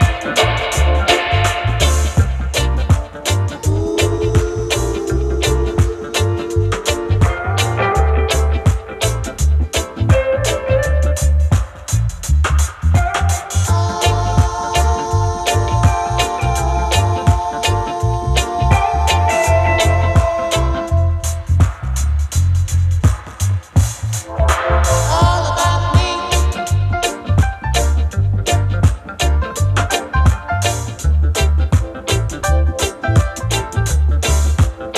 Жанр: Регги
Reggae